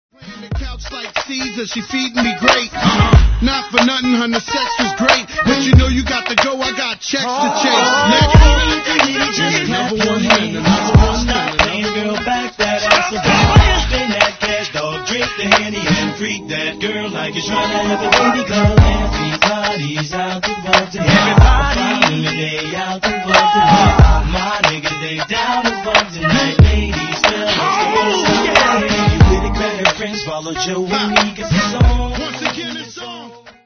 90’S HIPHOP
思わずクラップしたくなるナイスグルーヴ!!